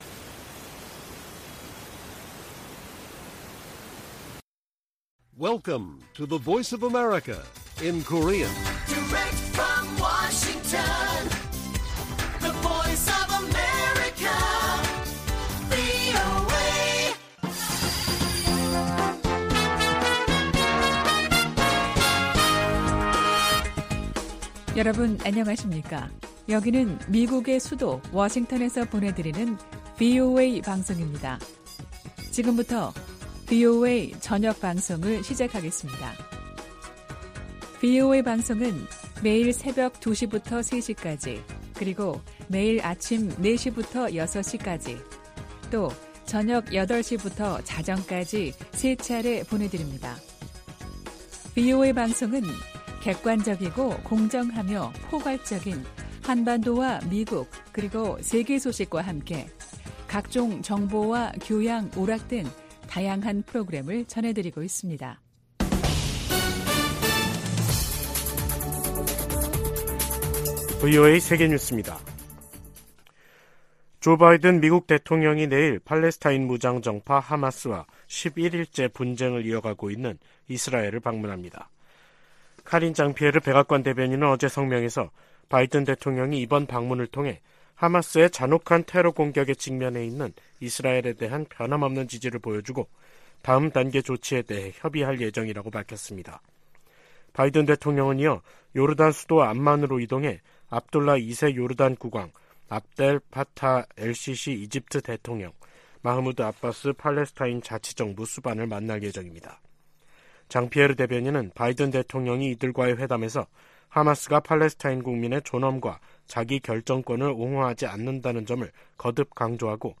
VOA 한국어 간판 뉴스 프로그램 '뉴스 투데이', 2023년 10월 17일 1부 방송입니다. 북한과 러시아 간 정상회담에 이어 러시아 외무장관의 방북 계획이 발표되면서 양측 관계가 한층 긴밀해지는 양상입니다. 북한 라진항에서 러시아로 무기를 운송한 화물선이 미국 정부의 제재를 받고 있는 선박으로 나타났습니다. 북한이 미국 본토 미사일 방어망을 무력화시킬 만큼 많은 핵 탑재 대륙간 탄도미사일 배치에 속도를 내고 있다는 미 의회 산하 기구 평가가 나왔습니다.